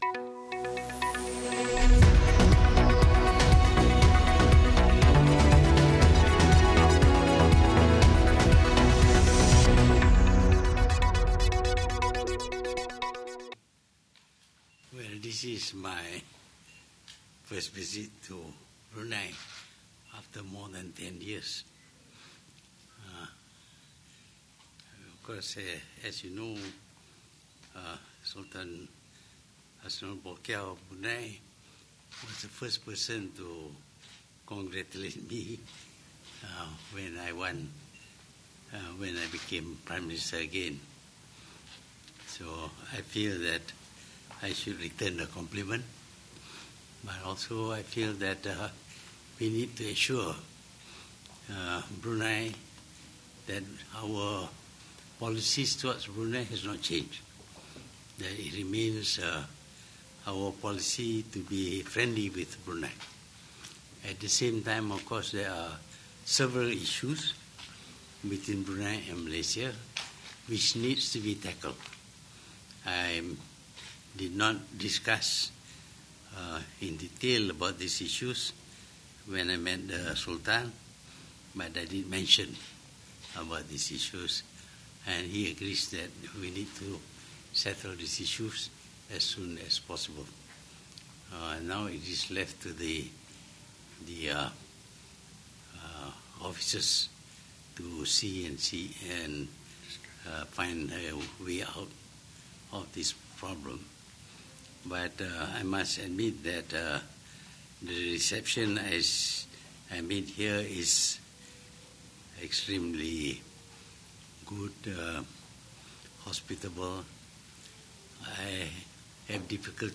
Sidang Media Khas Perdana Menteri di Brunei
Berikut merupakan rakaman sidang media khas oleh Perdana Menteri Tun Dr Mahathir Mohamad di Bandar Seri Begawan, Brunei bertarikh 3 September 2018.